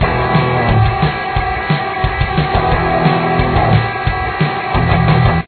This riff is pretty standard and is in drop D tuning.